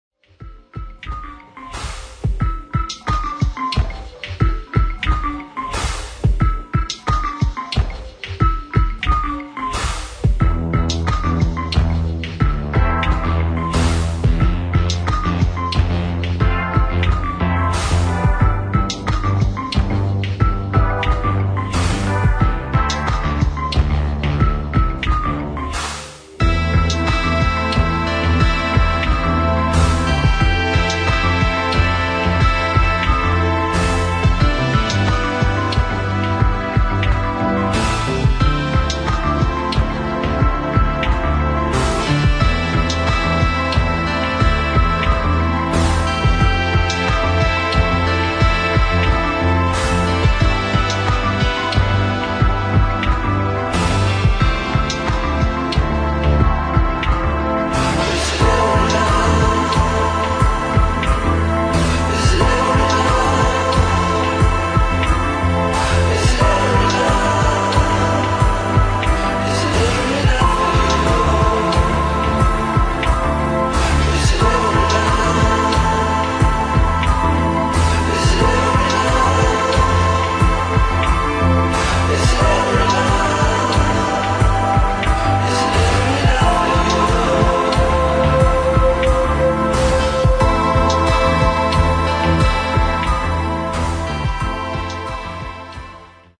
[ HOUSE / ELECTRONIC ]